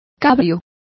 Complete with pronunciation of the translation of rafters.